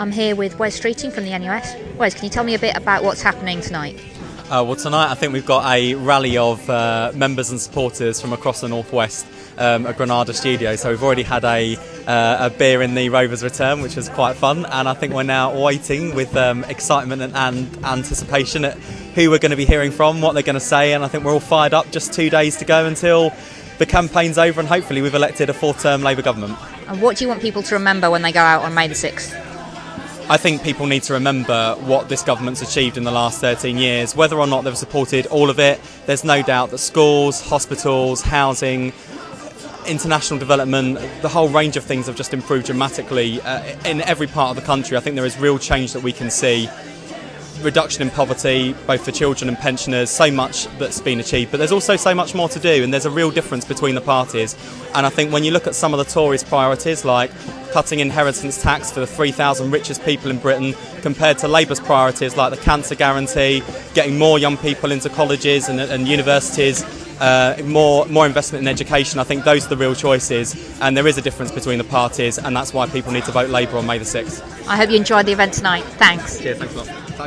Wes Streeting, NUS, at the Labour Rally in Manchester